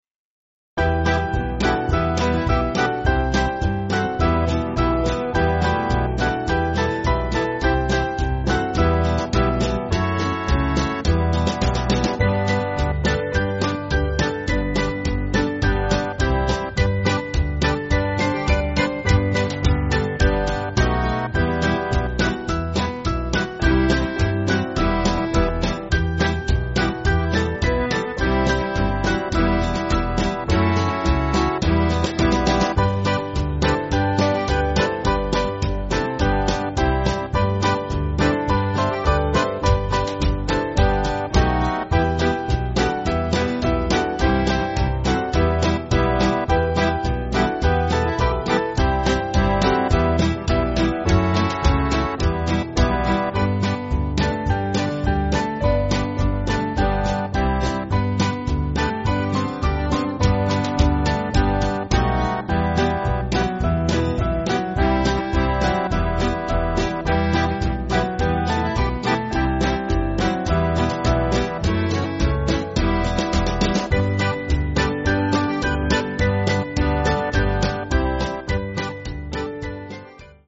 Small Band
(CM)   5/Em-Fm